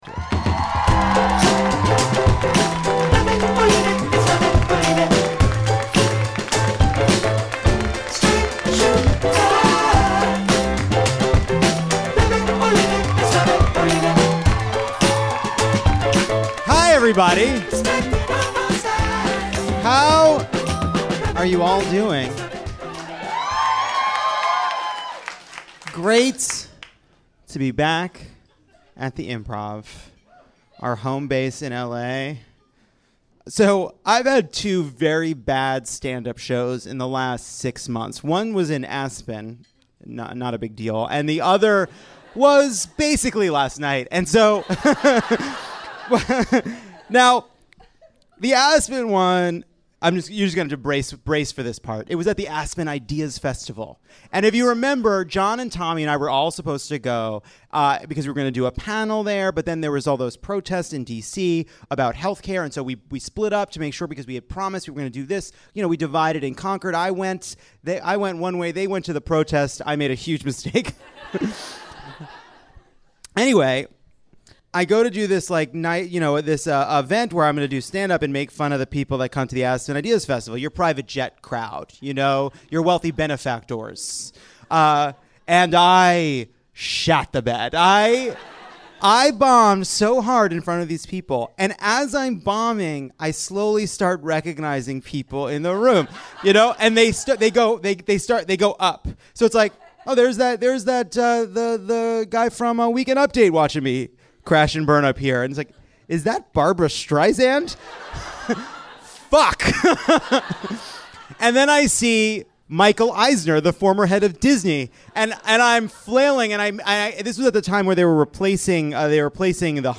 Plus D'Arcy Carden from NBC's The Good Place stops by to take on the role of a lifetime and we play an Oscars game you won't want to miss.